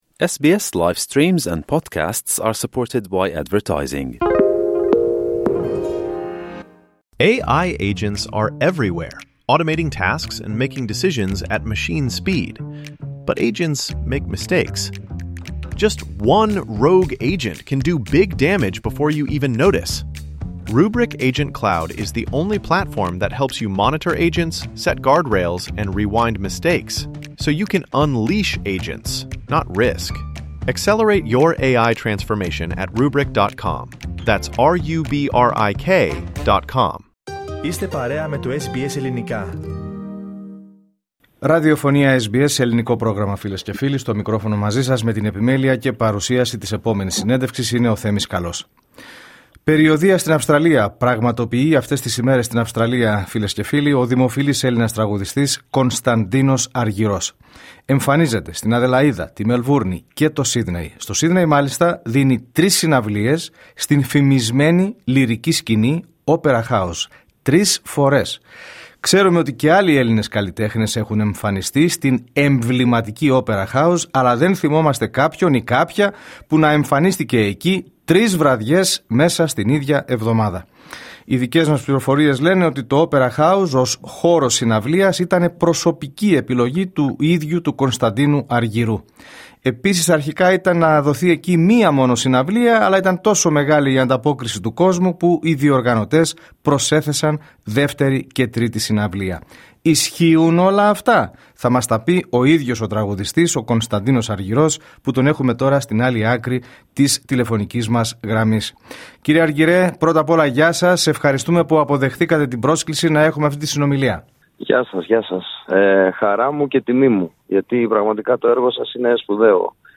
Όπως αποκάλυψε ο ίδιος στη συνέντευξή του στο πρόγραμμά μας, η επιλογή του συγκεκριμένου χώρου ήταν προσωπική του επιθυμία.